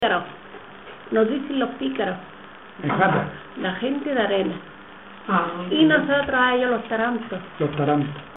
Materia / geográfico / evento: Refranes y proverbios Icono con lupa
Játar (Granada) Icono con lupa
Secciones - Biblioteca de Voces - Cultura oral